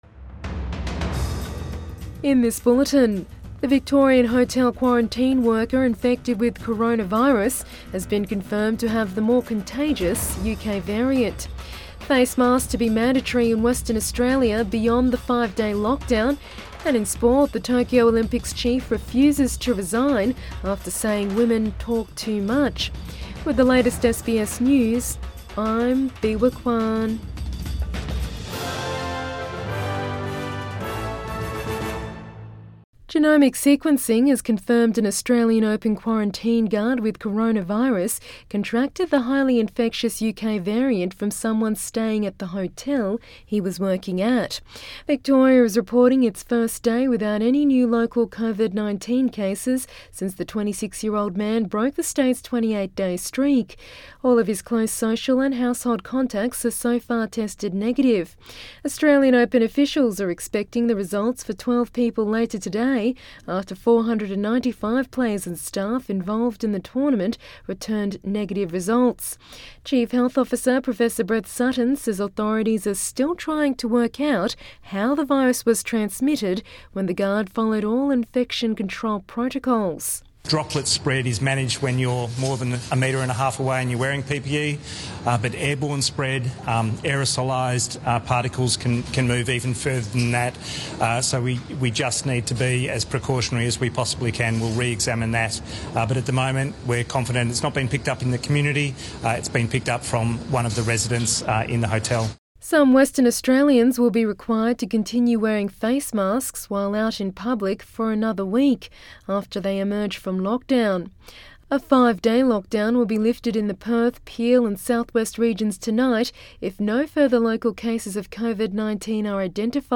Midday bulletin 5 February 2021